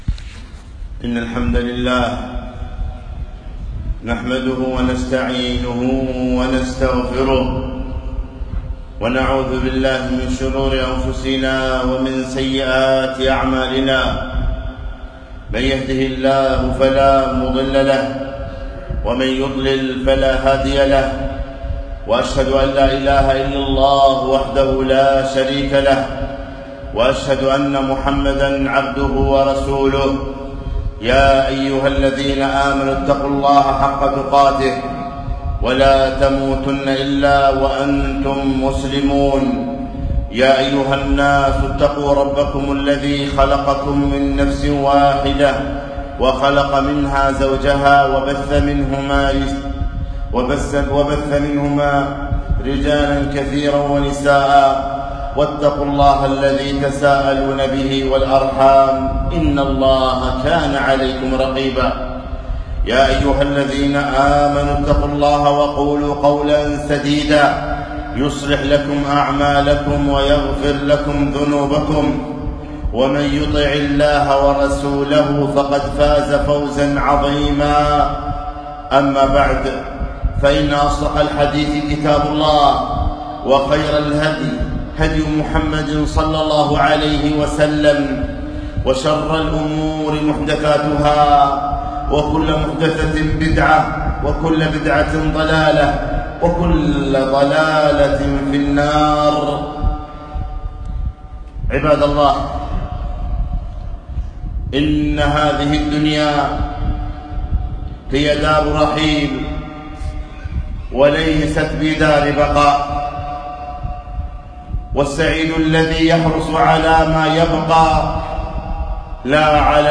خطبة - لاتغفل عن قلبك